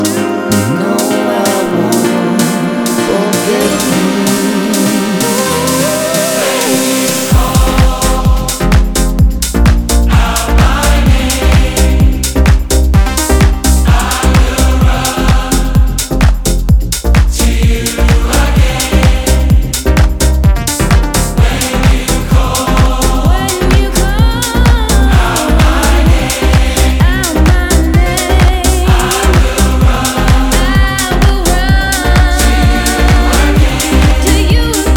House Dance